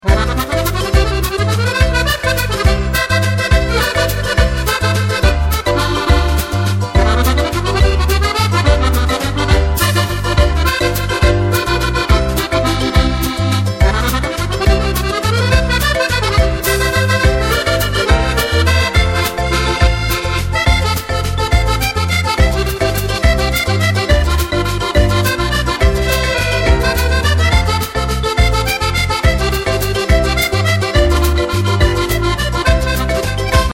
13 morceaux d’accordéons pour danser
la valse mexicaine
valse Mexicaine